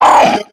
Cri de Gloupti dans Pokémon X et Y.